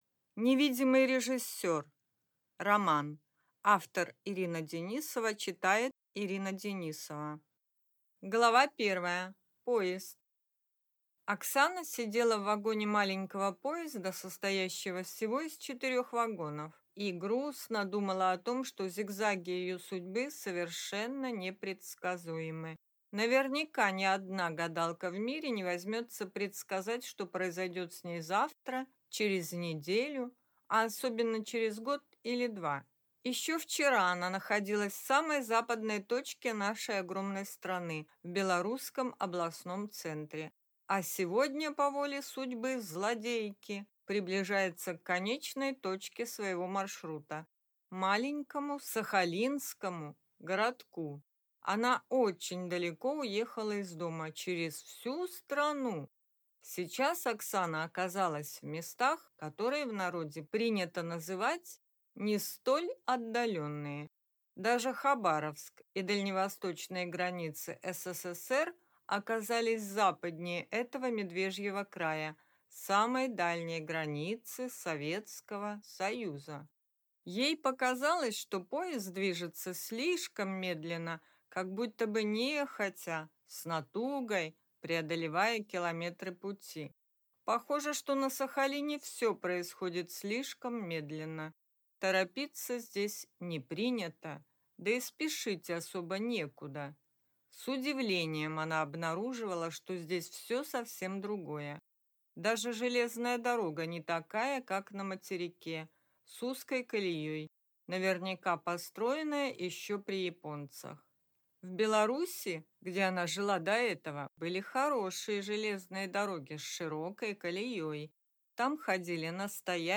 Аудиокнига Невидимый режиссер | Библиотека аудиокниг